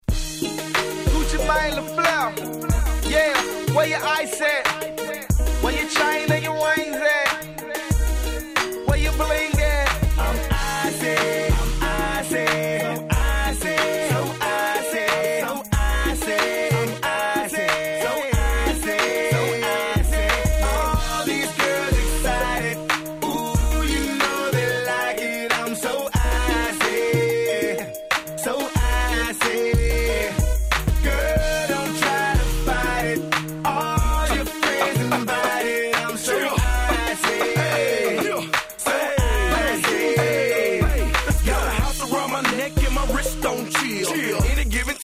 05' Smash Hit Southern Hip Hop !!
Auto Tuneブリブリのサビが何とも格好良いですね。